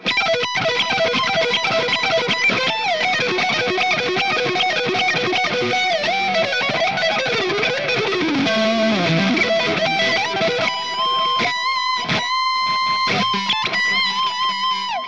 Lead
RAW AUDIO CLIPS ONLY, NO POST-PROCESSING EFFECTS